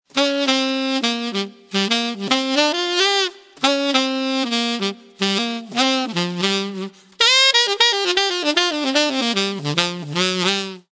TSAXSOLO.mp3